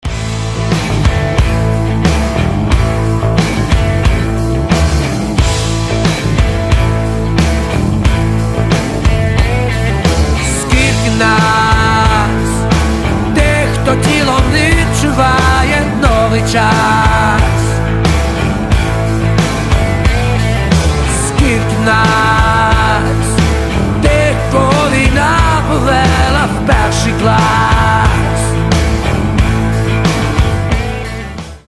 • Качество: 320, Stereo
громкие
Alternative Rock
украинский рок
indie rock